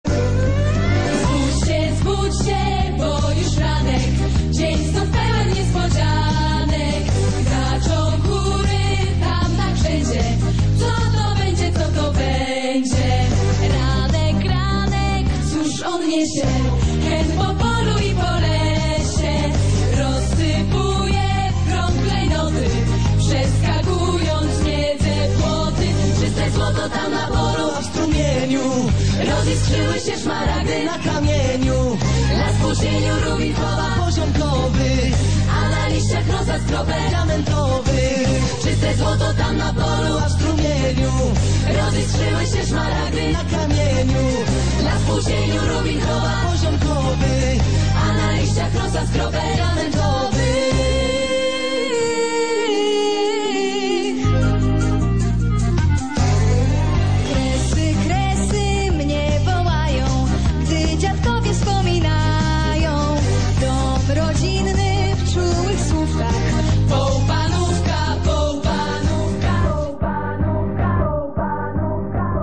piosenka śląska